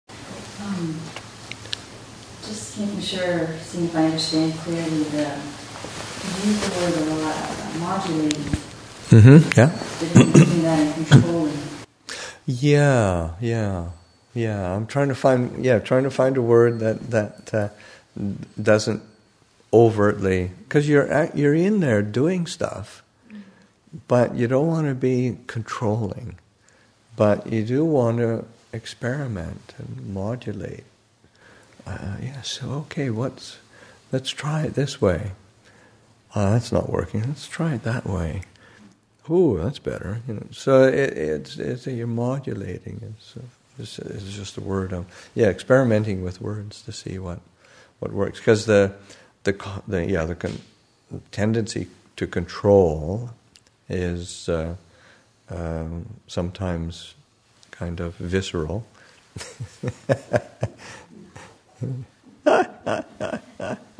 Abhayagiri Buddhist Monastery in Redwood Valley, California
[54:45] “[Question unclear] Is modulating a good way to describe working with the breath?”